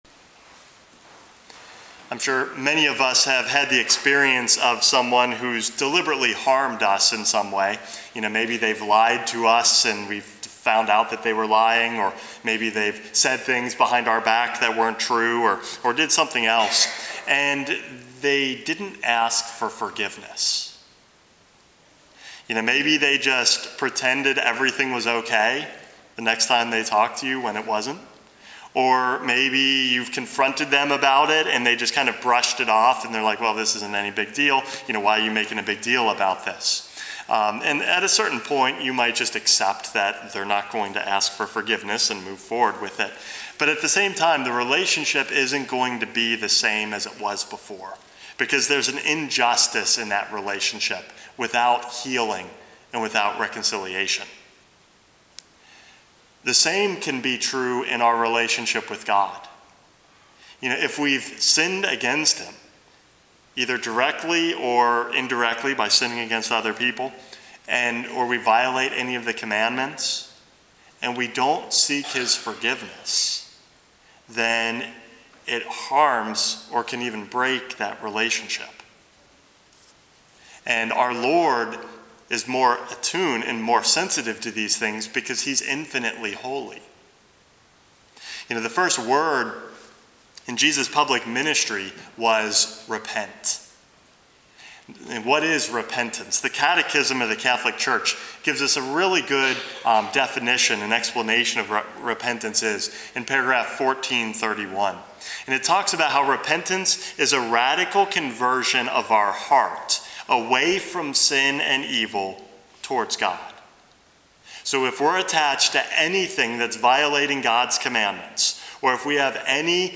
Homily #441 - The Power of Repentance